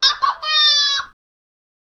Royalty-free creature-vocalizations sound effects
I need a short SFX for a non-humanoid charcter that communicates through chimes.
pokemon-cry-of-a-young-l3gilf6d.wav